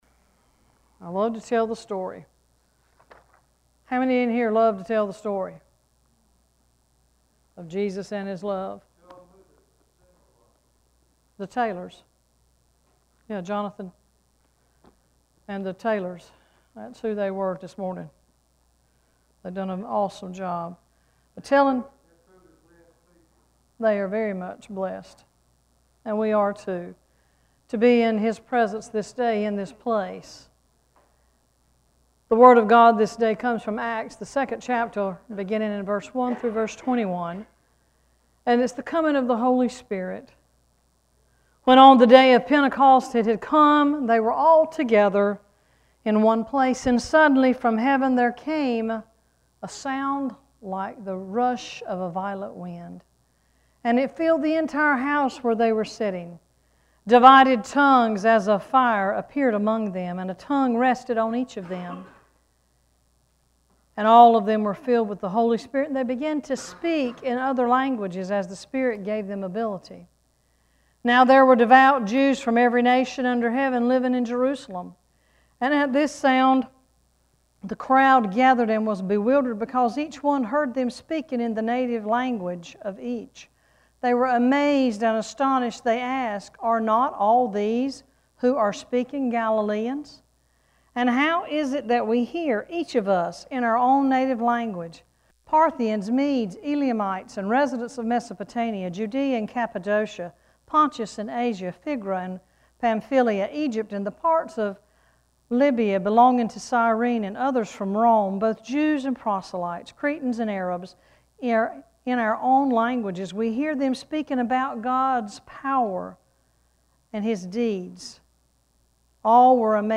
Worship Service 6-4-17: “Birthday Stories”